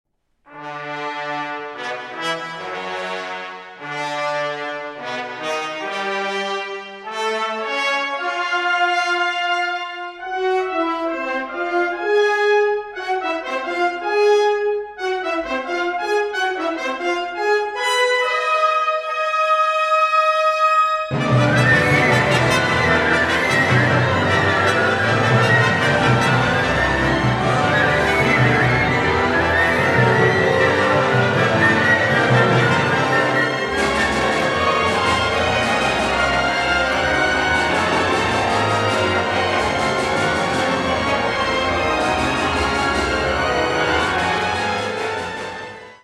First Recordings, Live